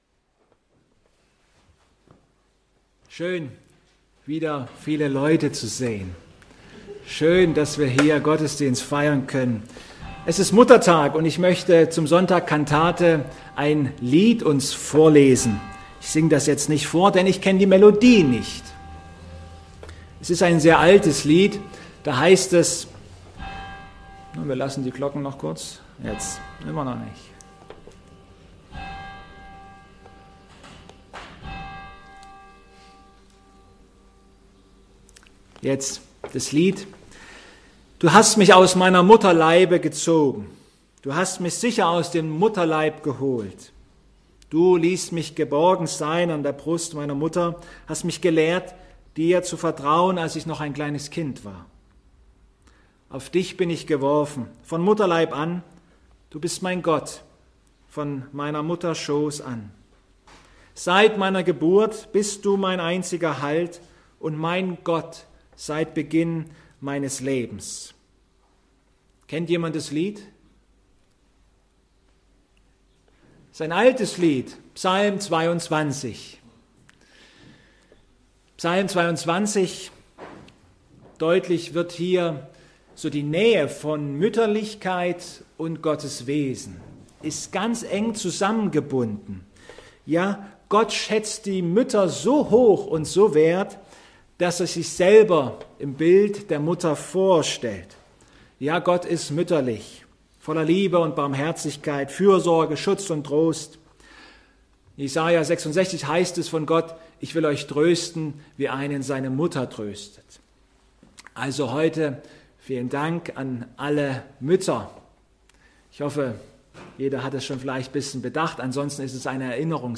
Ps 23,3+4 Gottesdienstart: Online-Gottesdienst Der Gottesdienst vom Sonntag Kantate